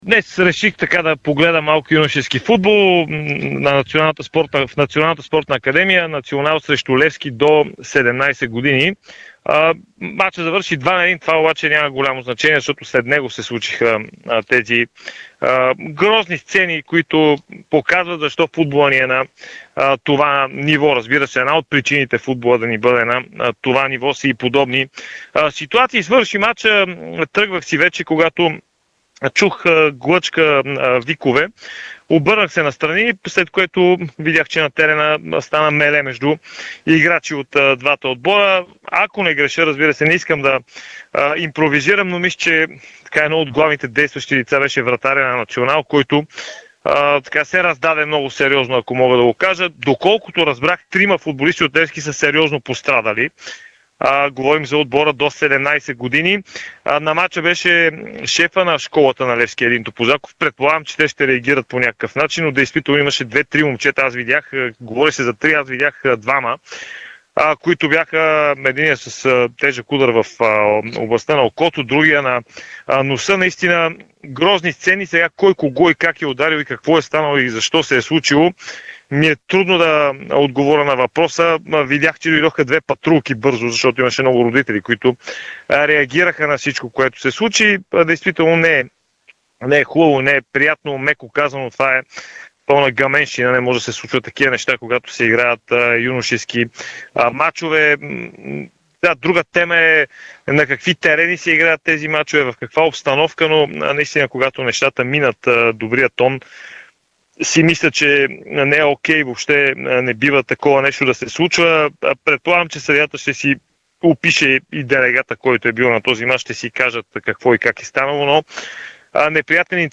Разказ